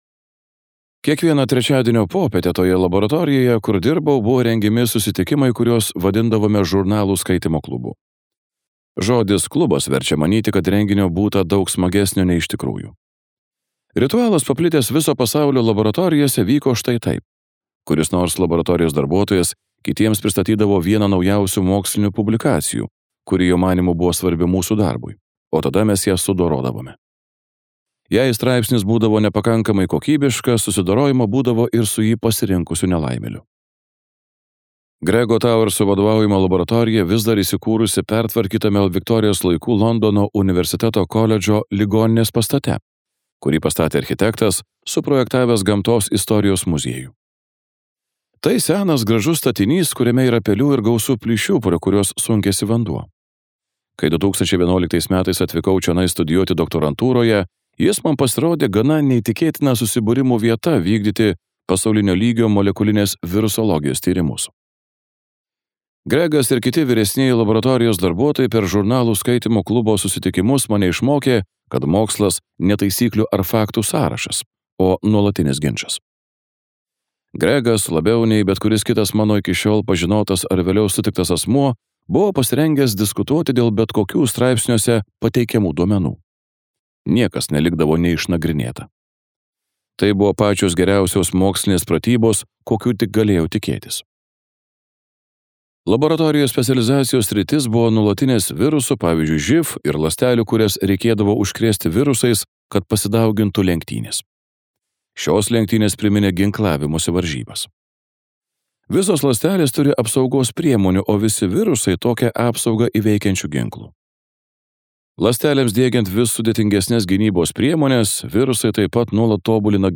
Skaityti ištrauką play 00:00 Share on Facebook Share on Twitter Share on Pinterest Audio Ultraperdirbti žmonės.